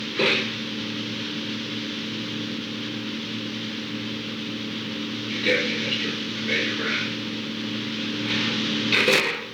Secret White House Tapes
Location: Executive Office Building
The President talked with the White House operator.